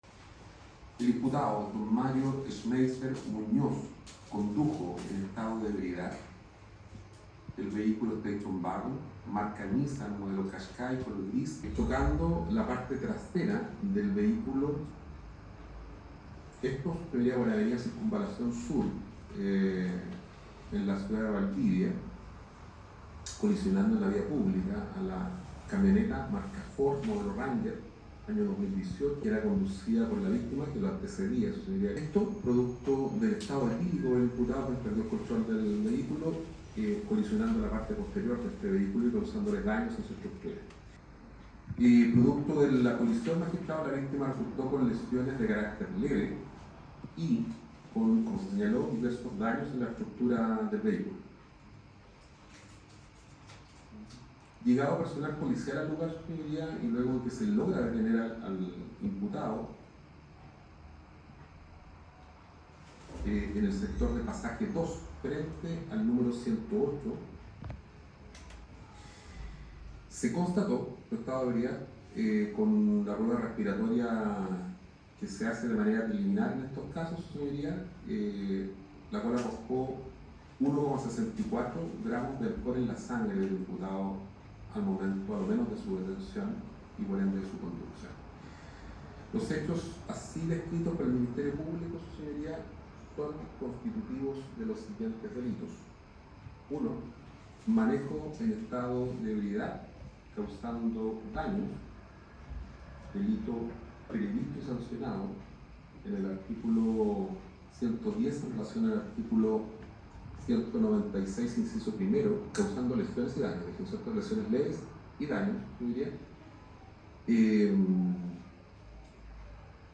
Audiencia-formalización-MEE-13-diciembre-25.mp3